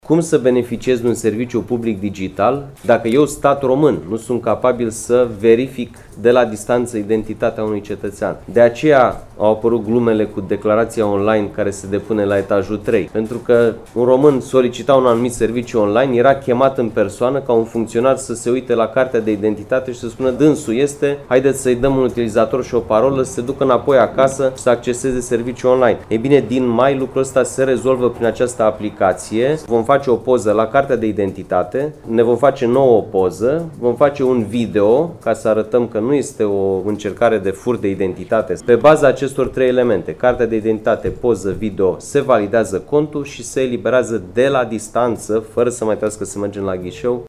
Prezent la Timișoara, ministrul Digitalizării, Sebastian Burduja, a explicat că prin noul sistem cetățenii nu vor mai fi nevoiți să meargă la ghișeu pentru a obține un user și o parolă pe baza cărora să poată accesa anumite servicii publice online.